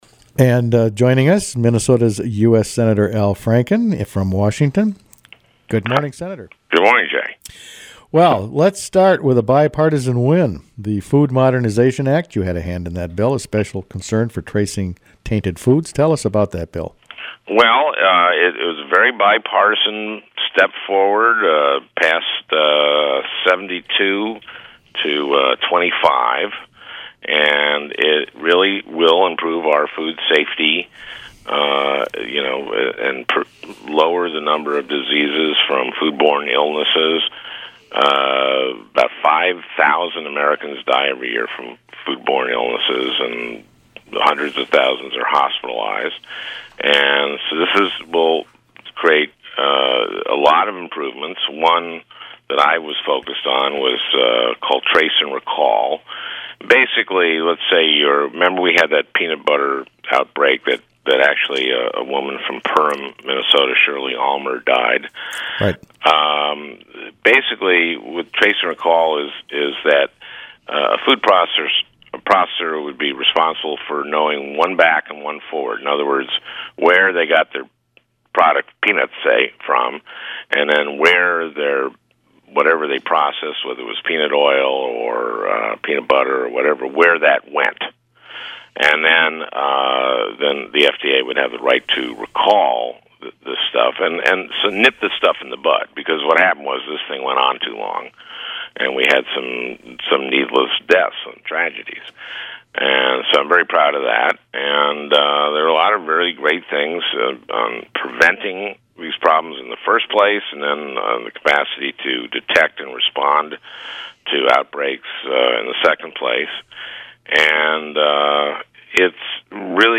Sen. Al Franken interviewed on WTIPs "Daybreak"